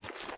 STEPS Dirt, Walk 12.wav